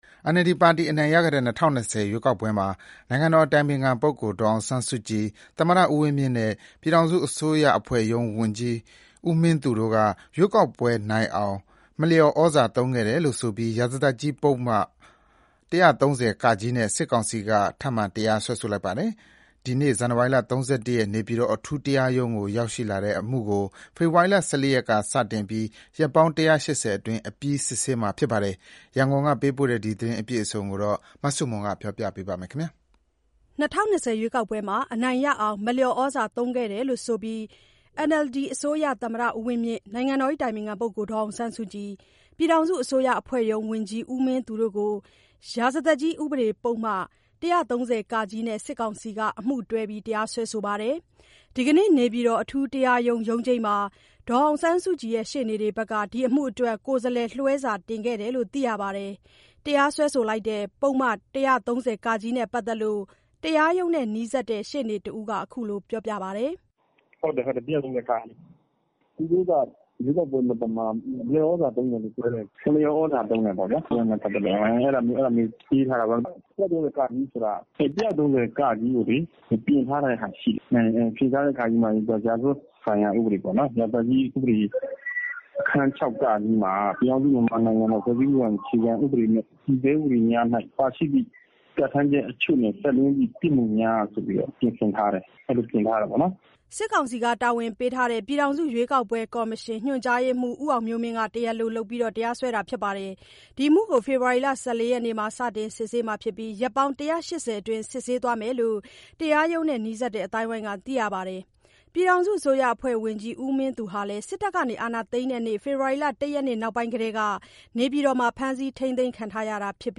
၂၀၂၀ ရွေးကောက်ပွဲမှာ အနိုင်ရအောင် မလျော်သြဇာသုံးခဲ့တယ်လို့ဆိုပြီး NLD အစိုးရ သမ္မတဦးဝင်းမြင့်၊ နိုင်ငံတော်အတိုင်ပင်ခံ ဒေါ်အောင်ဆန်းစုကြည်၊ ပြည်ထောင်စုအစိုးရအဖွဲ့ရုံး ဝန်ကြီးဦးမင်းသူတို့တို့ ရာဇသတ်ကြီး ဥပဒေပုဒ်မ ၁၃၀-က နဲ့ စစ်ကောင်စီက အမှုတွဲပြီး တရားစွဲပါတယ်။ ဒီနေ့နေပြည်တော်အထူးတရားရုံး ရုံးချိန်းမှာ ဒီအမှုအတွက် ဒေါ်အောင်ဆန်းစုကြည်ရှေ့နေတွေ ကိုယ်စားလှယ်လွှဲစာ တင်ခဲ့တယ်လို့ သိရပါတယ်။ တရားစွဲတဲ့ ပုဒ်မ ၁၃၀-က နဲ့ပတ်သက်လို့ တရားရုံးနဲ့နီးစပ်တဲ့ ရှေ့နေတဦးက ခုလိုပြောပြပါတယ်။